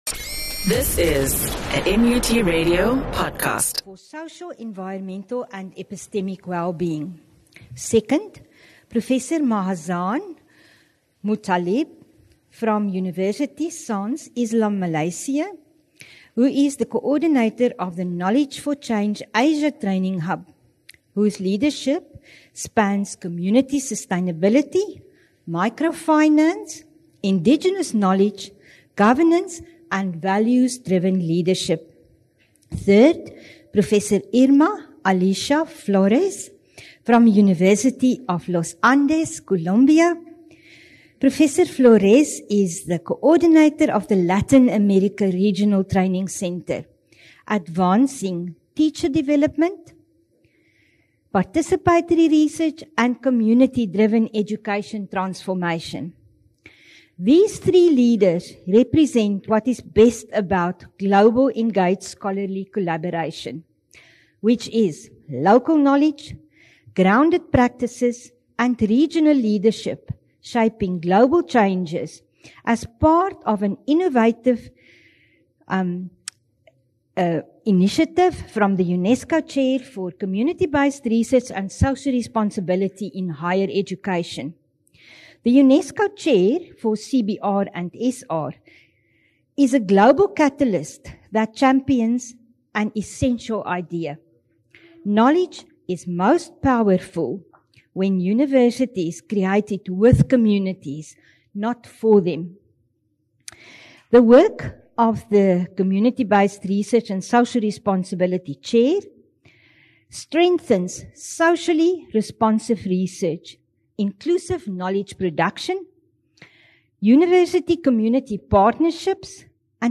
19 Nov MUT CEAD Hosted Scholarship of Engagement Conference